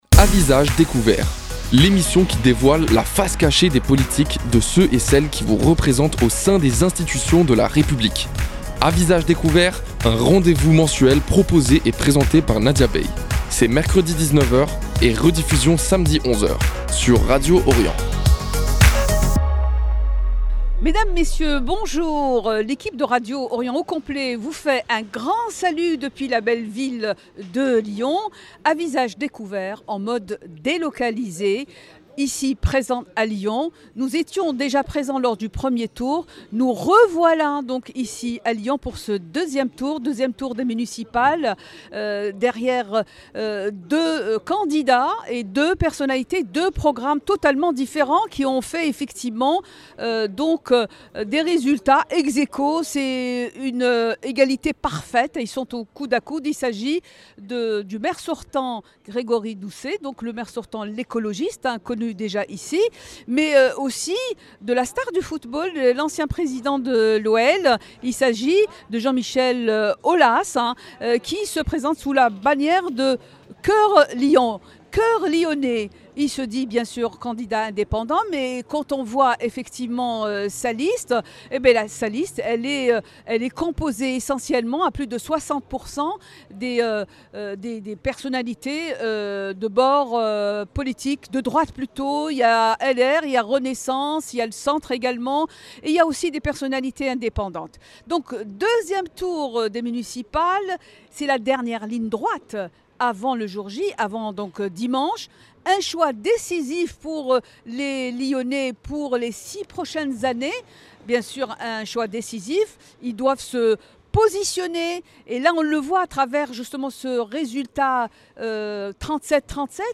Dans cette édition spéciale d’À Visage Découvert consacrée aux municipales 2026 à Lyon, deux figures majeures s’expriment : le maire sortant écologiste Grégory Doucet et le candidat Jean-Michel Aulas . Urbanisme, sécurité, qualité de vie, mais aussi attractivité économique, emploi et rayonnement : chacun expose sa vision et ses priorités pour l’avenir de la capitale des Gaules.